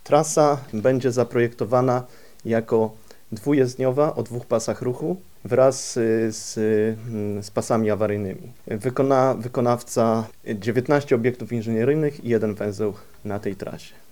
na-strone_3_dyrektor-GDDKiA-aspekt-techniczny.mp3